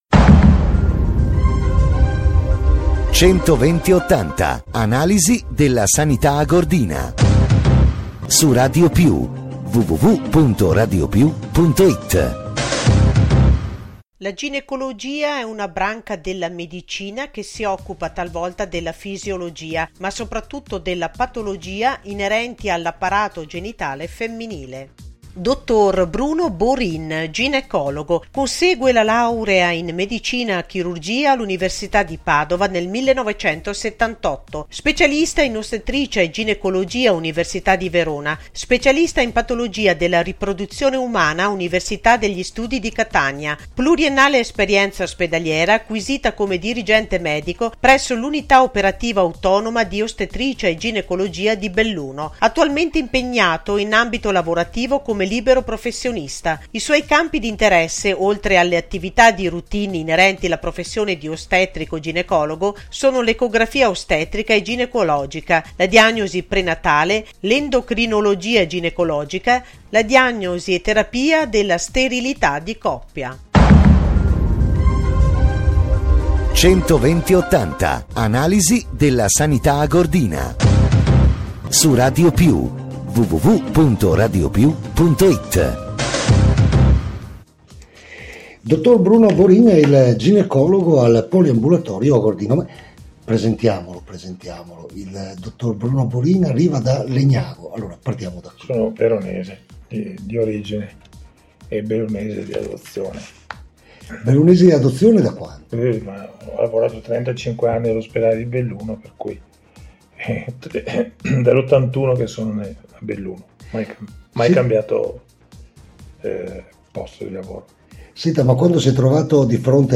audio – L’INTERVISTA